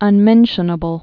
(ŭn-mĕnshə-nə-bəl)